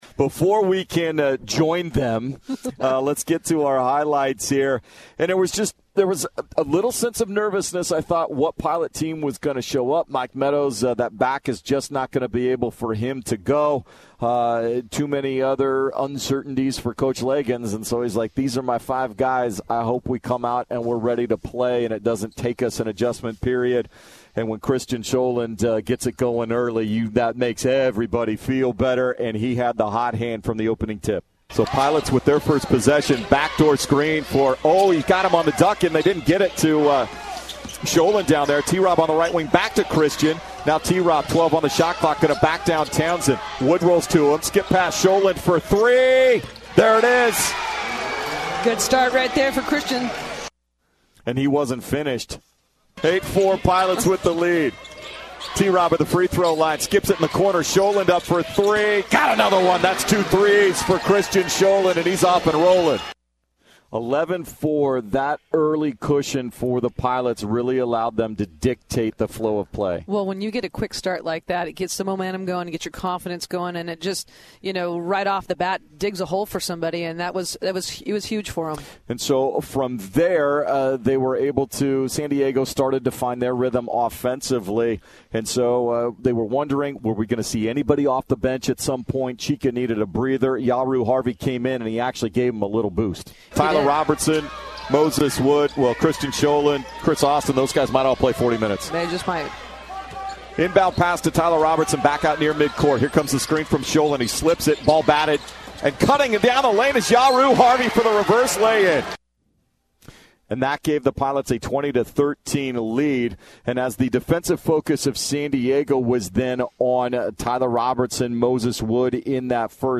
Radio Highlights vs. San Diego at WCC Tournament